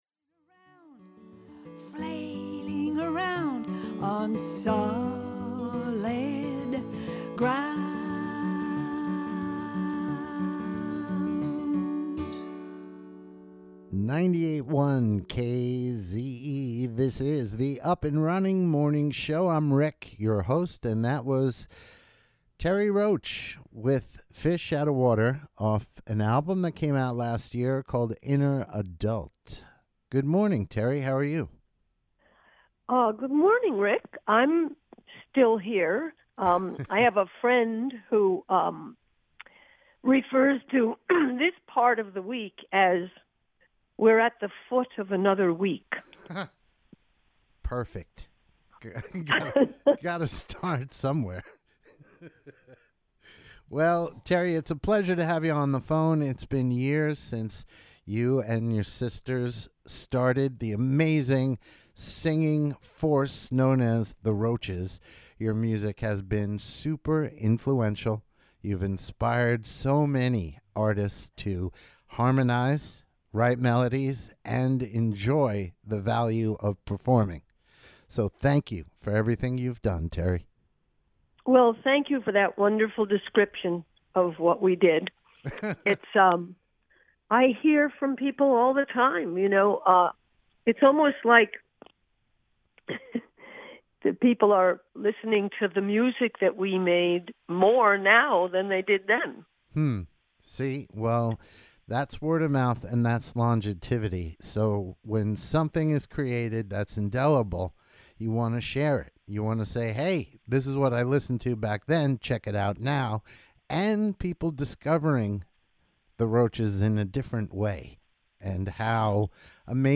Terre Roche interview on WKZE Radio — Terre Roche